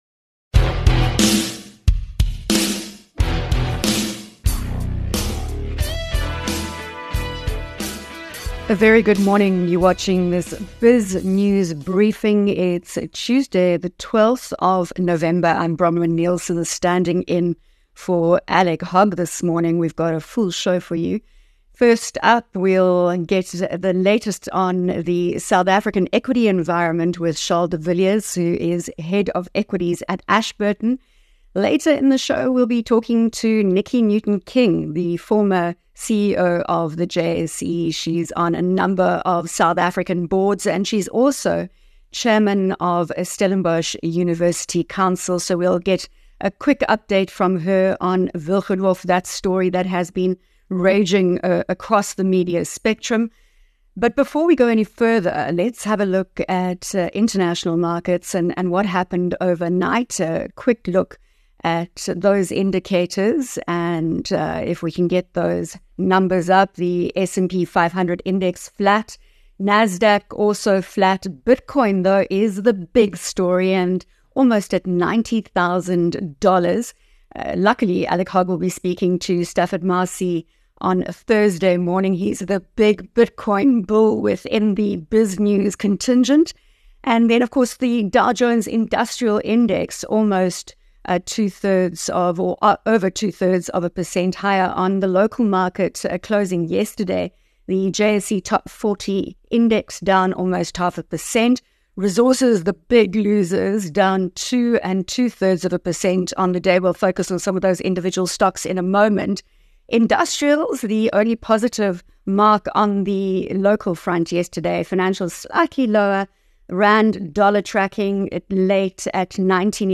Cosmic Radio Interview